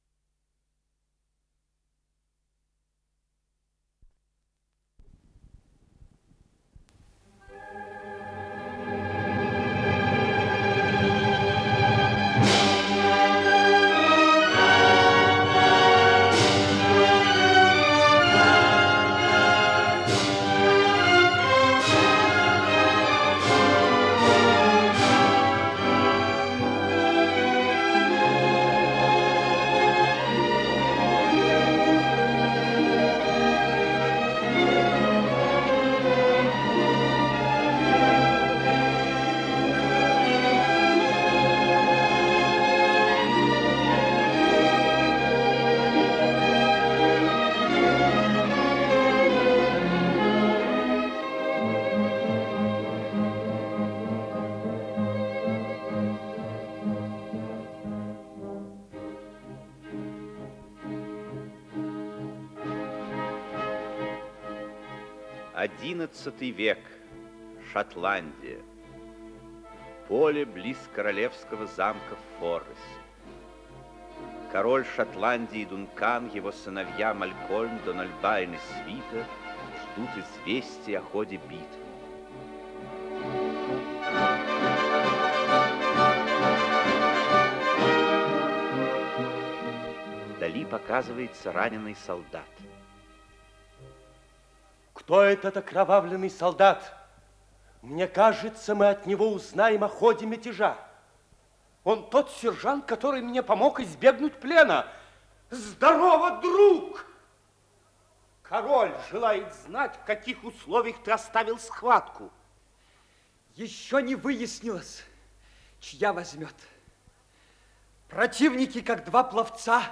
Исполнитель: Артисты Малого театра